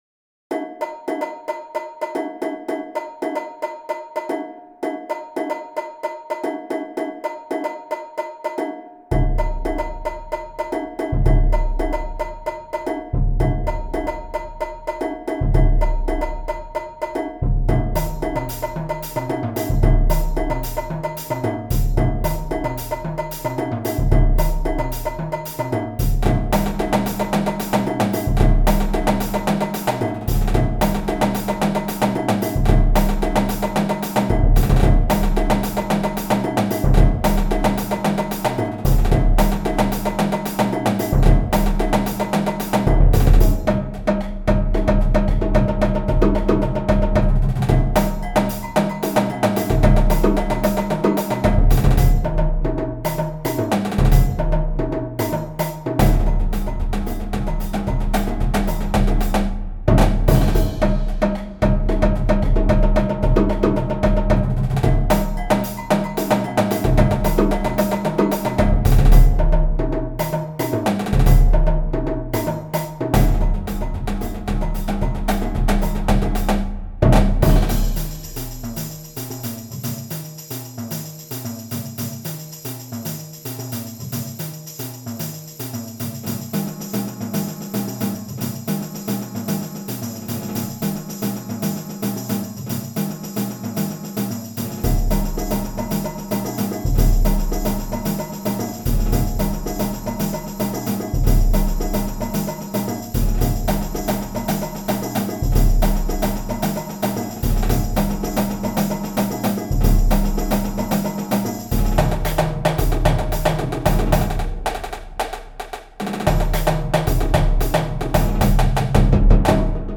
Jazz & Latin